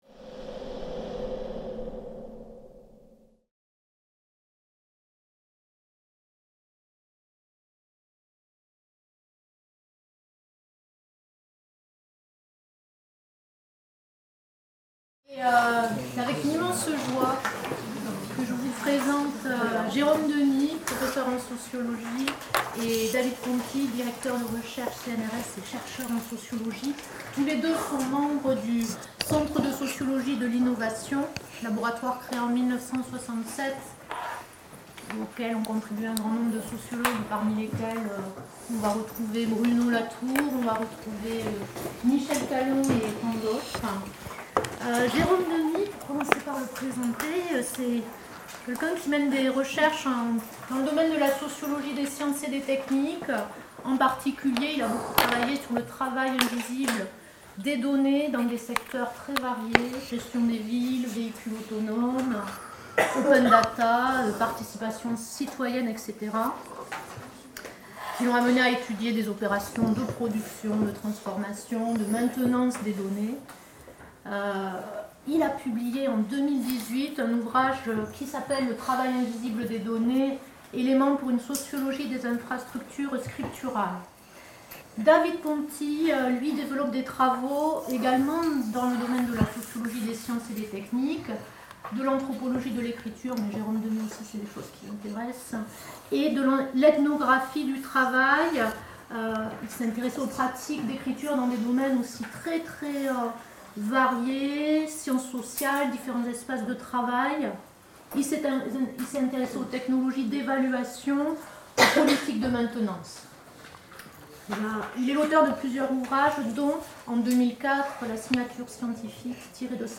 Séminaire Invités Master de Sociologie